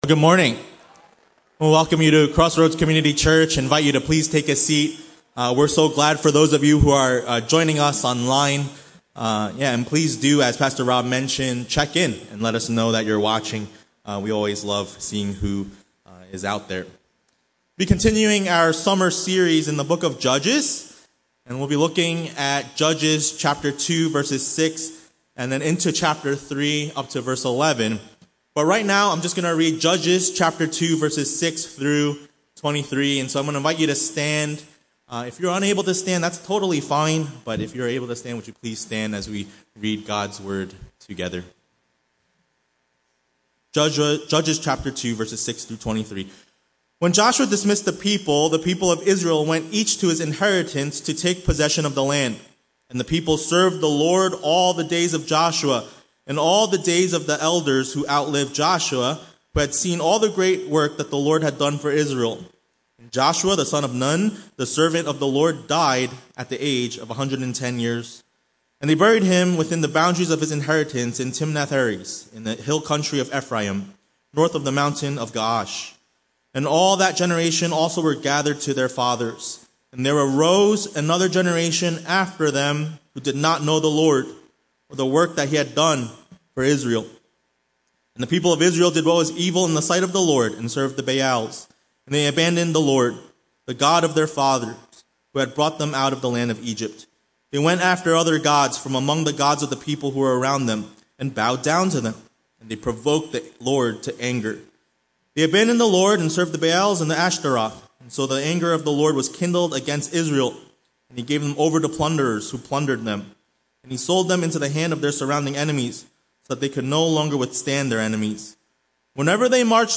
A message from the series "Judges."